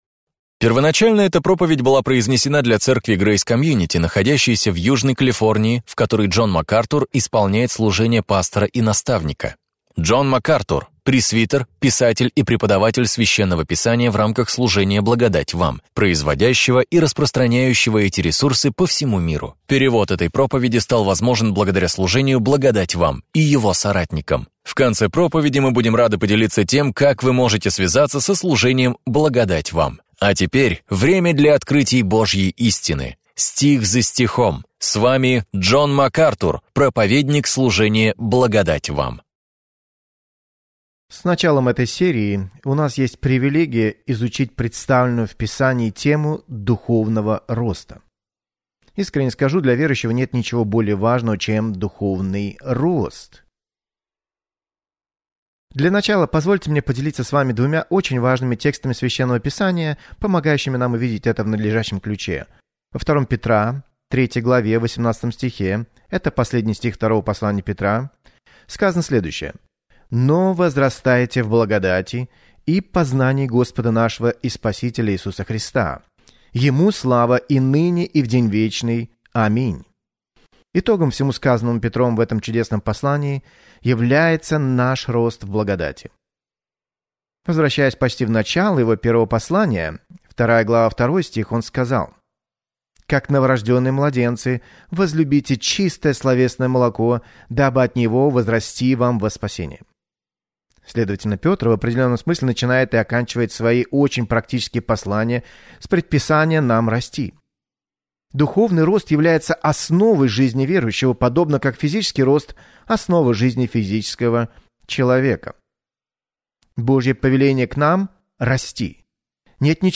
В своей проповеди «Возвращение к основам», актуальной для всякого времени, Джон Макартур делает обзор базовых принципов христианства и помогает вам сделать их основанием для своей жизни.